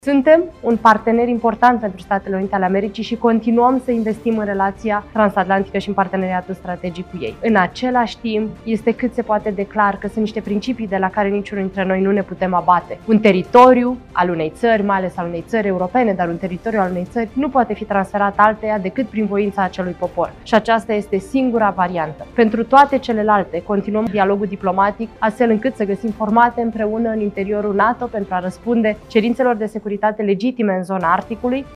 Un teritoriu al unei țări nu poate fi transferat alteia decât prin voința acelui popor, transmite ministra de Externe, Oana Țoiu, aflată la Forumul Economic Mondial de la Davos. Șefa diplomației române a reafirmat, printr-un mesaj video publicat pe Facebook, interesul României pentru o alianță NATO puternică și pentru menținerea solidă a parteneriatului transatlantic. Contextul internațional este marcat de tensiuni generate de pretențiile președintelui Donald Trump asupra Groenlandei.